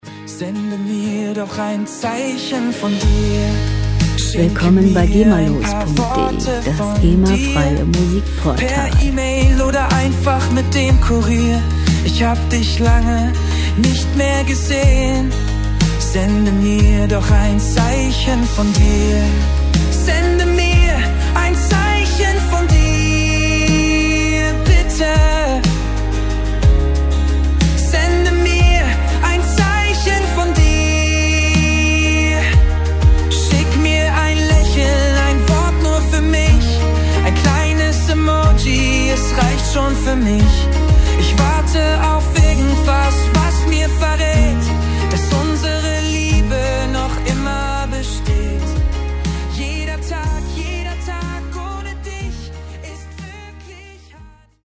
Pop Musik aus der Rubrik: "Popwelt Deutsch"
Musikstil: Ballade
Tempo: 92 bpm
Tonart: As-Dur
Charakter: sehnsuchtsvoll, abwartend
Instrumentierung: Sänger, Akustikgitarre, Synthesizer,